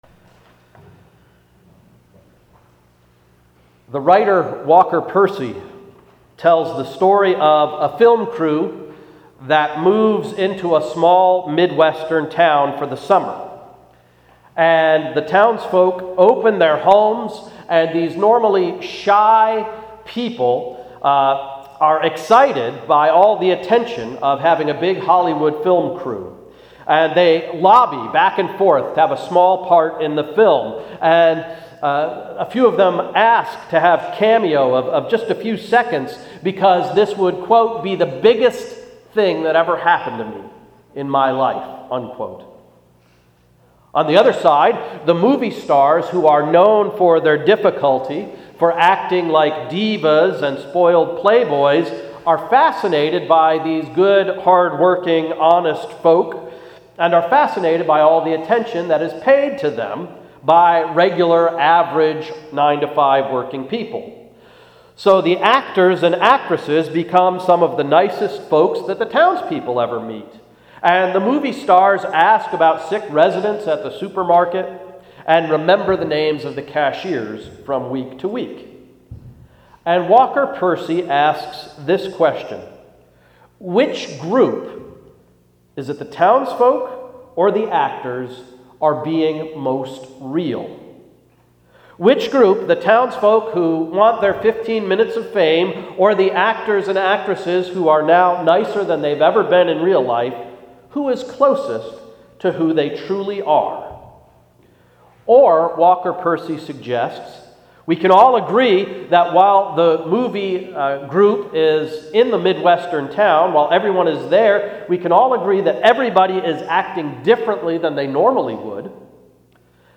Sermon of May 15th–Good Shepherd Sunday